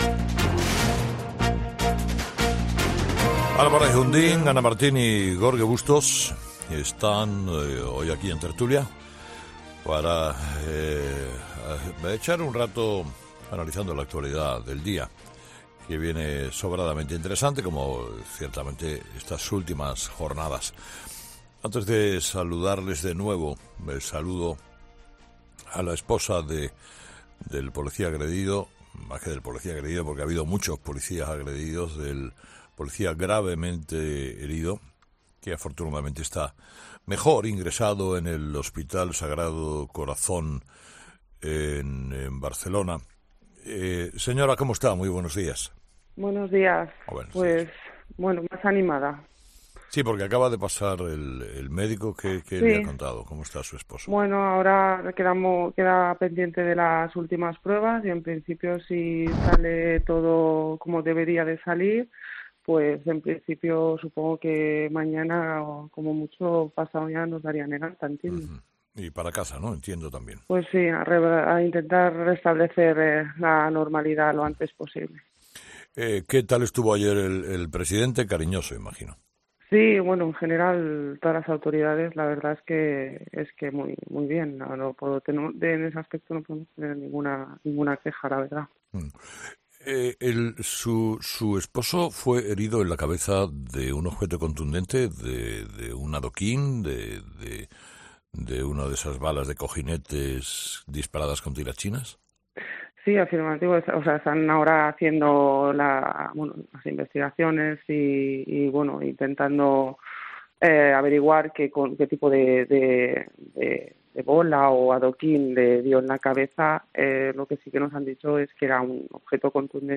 "Se me encoge el corazón cada vez que las veo", ha dicho en una entrevista este martes en "Herrera en COPE".